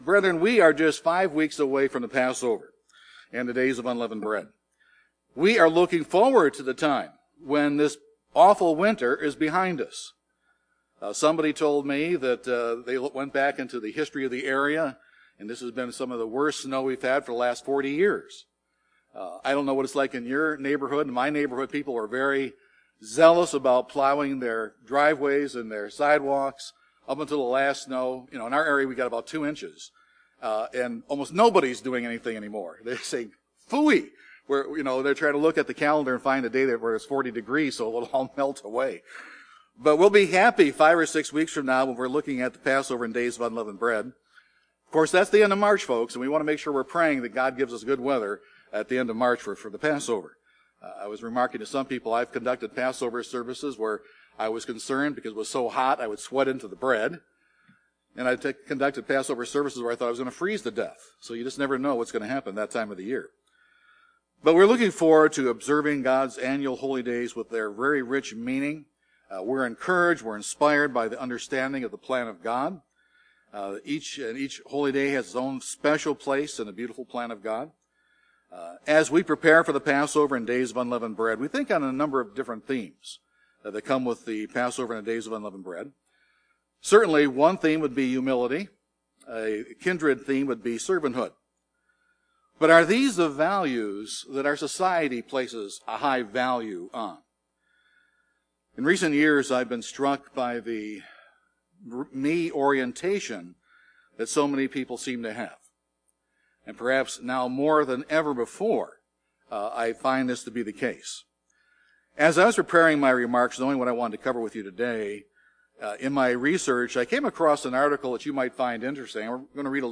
As we prepare for the Passover and the Days of Unleavened Bread, various themes come to mind, such as humility and servanthood. This sermon asks and answers the question: what are three essential keys to humble servanthood?